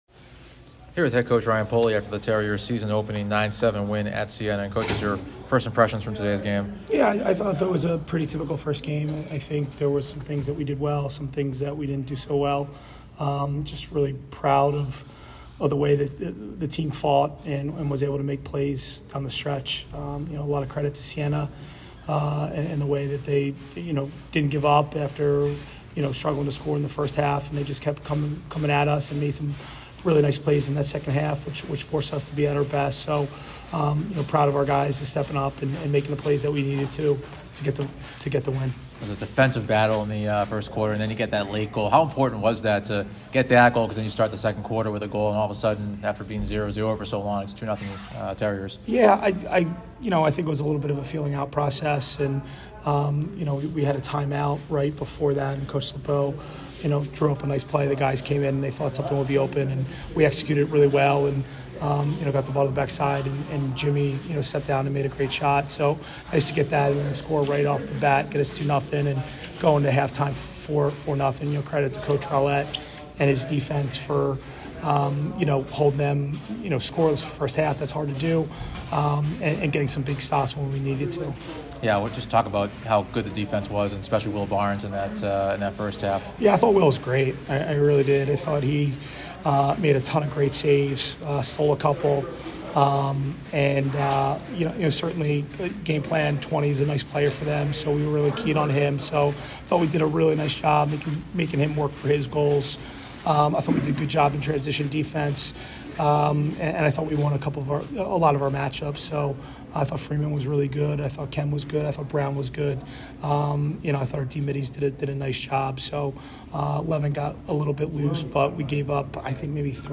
Men's Lacrosse / Siena Postgame (2-8-25)